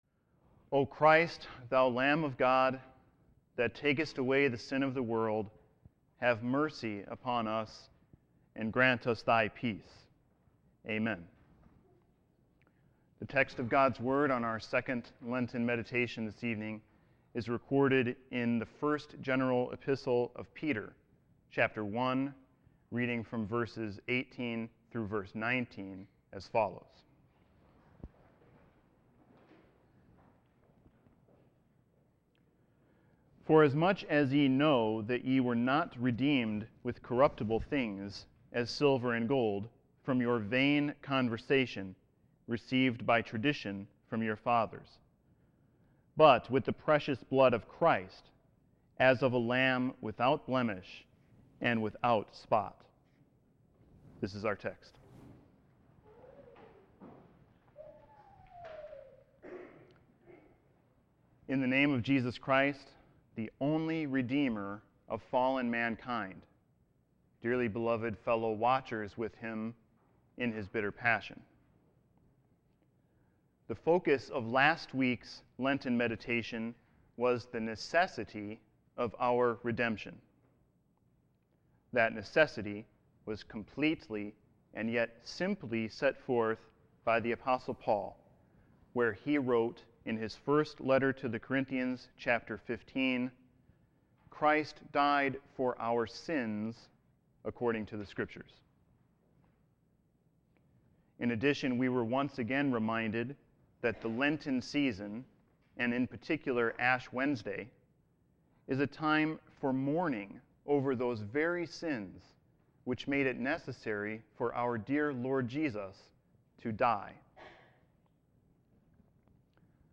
Sermon 3-12-14.mp3